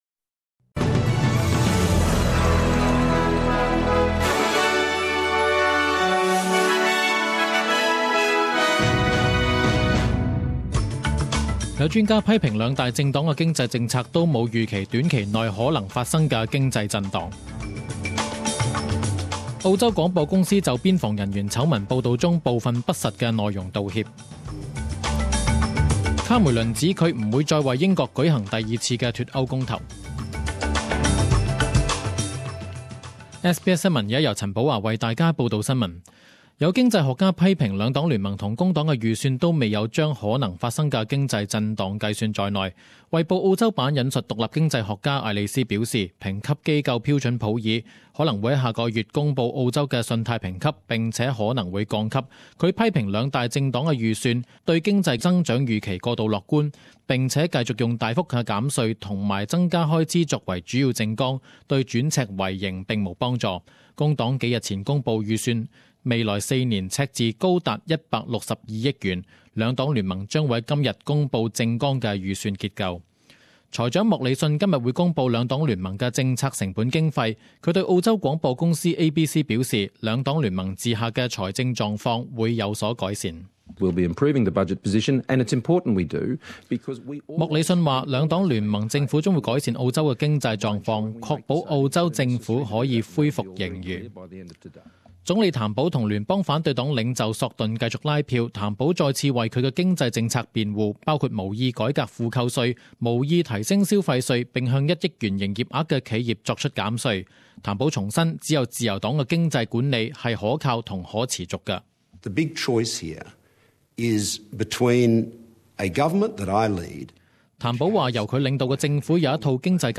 十点钟新闻报导（六月二十八日）